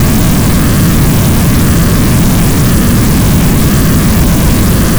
spaceEngineLarge_003.ogg